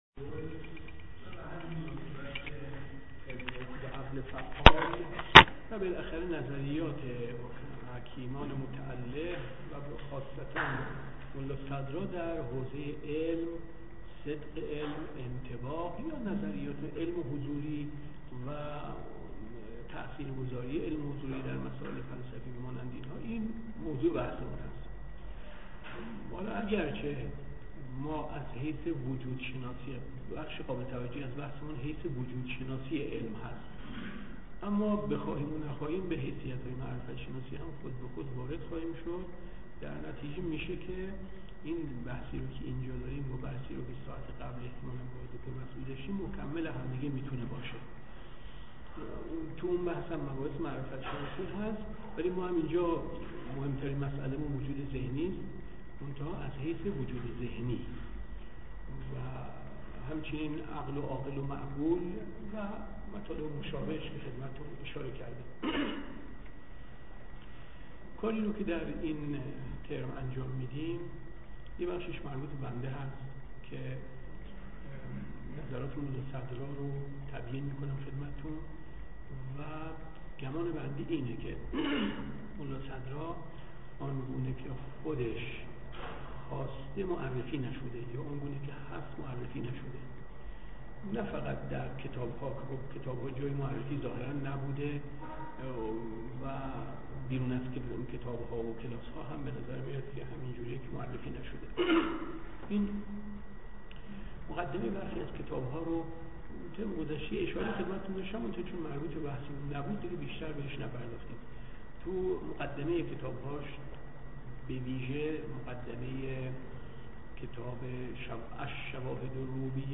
درس صوتی